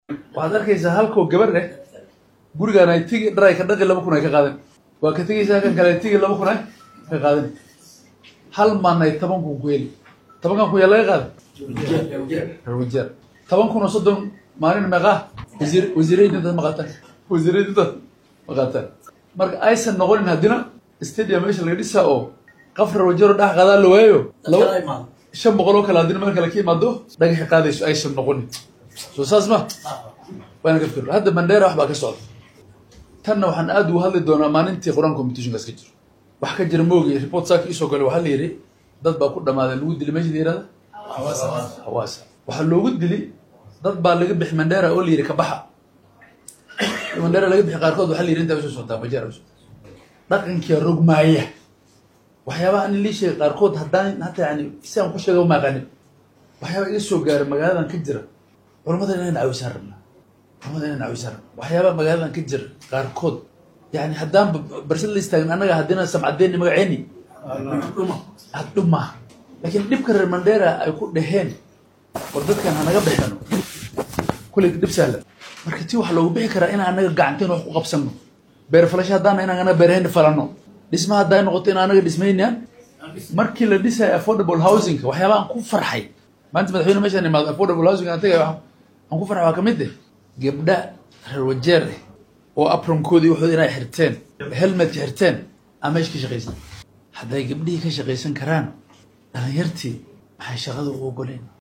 DHEGEYSO:Barasaabka Wajeer oo ka hadlay Itoobiyaanka laga soo saaray Mandera